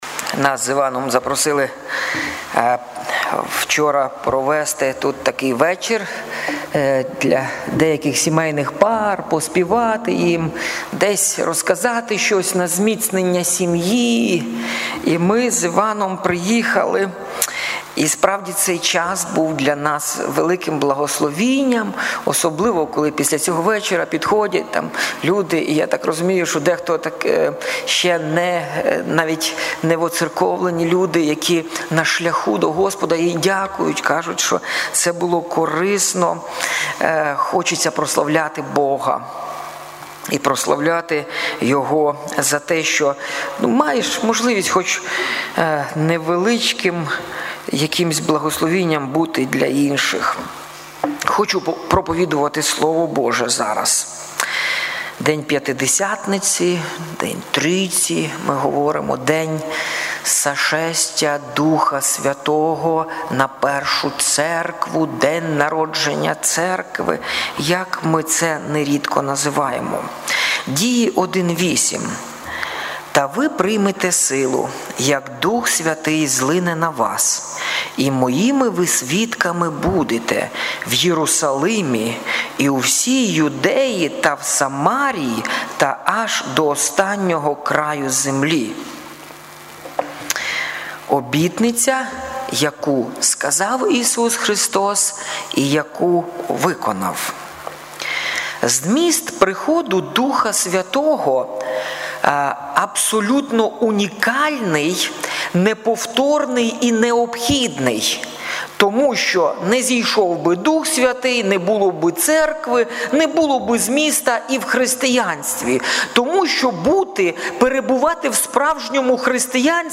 Трускавець Церква Преображення
тема проповіді День П'ятидесятниці